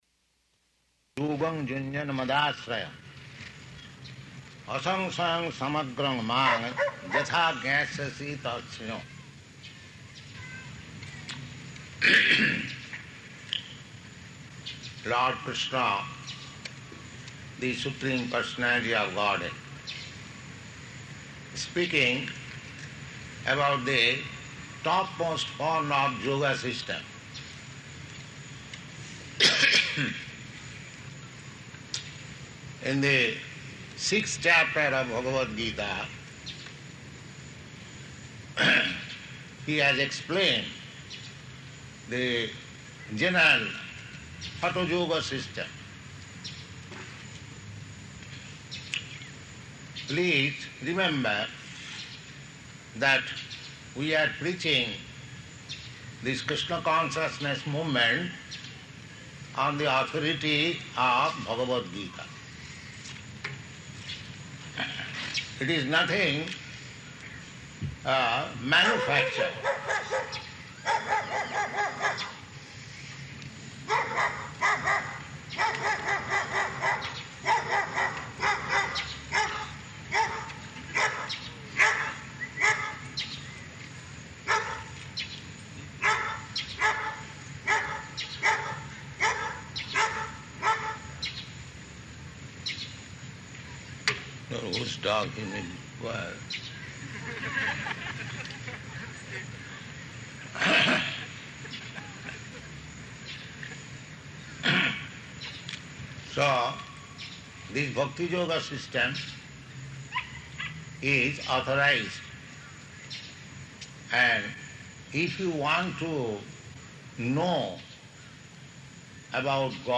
Location: Gainesville
[dog barking] [pause] Whose dog?
[laughter] So this bhakti-yoga system is authorized, and if you want to know about God, then you have to adopt this bhakti-yoga system.